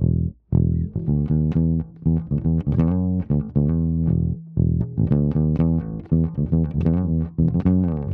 12 Bass PT2.wav